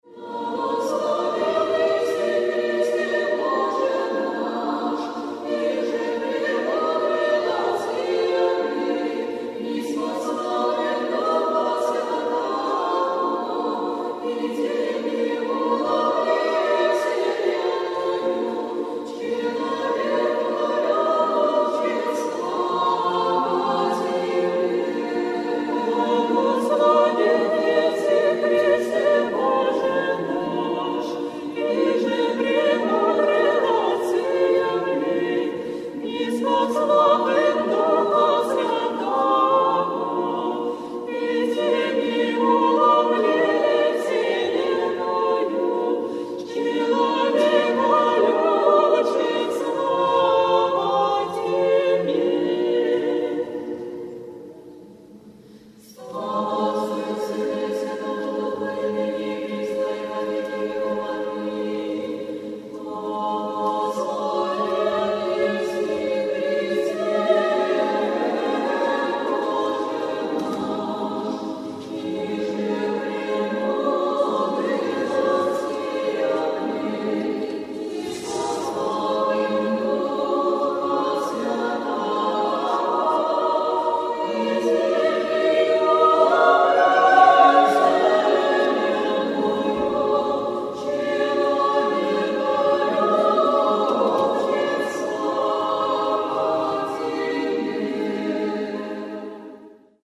III.Утреня 1.Тропари 1:40
1.tropari_0.mp3